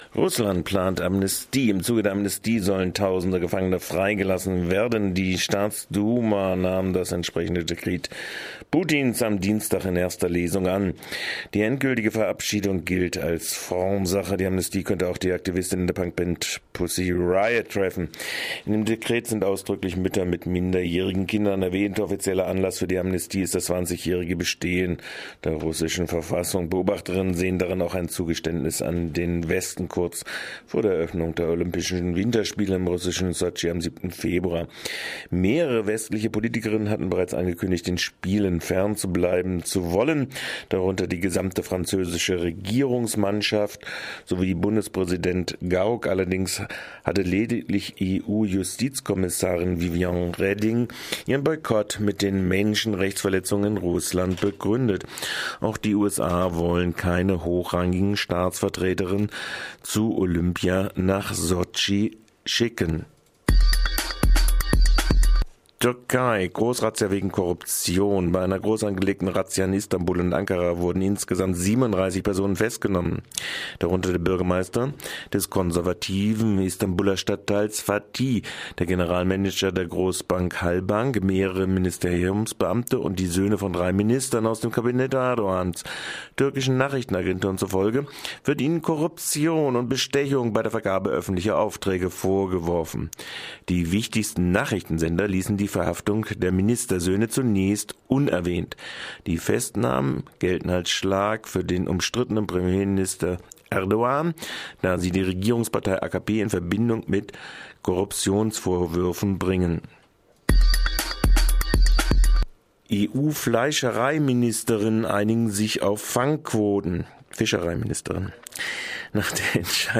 Focus Europa Nachrichten vom Mittwoch, den 18. Dezember - 9.30 Uhr